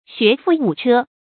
成語注音 ㄒㄩㄝˊ ㄈㄨˋ ㄨˇ ㄔㄜ
成語拼音 xué fù wǔ chē
學富五車發音
成語正音 車，不能讀作“cē”。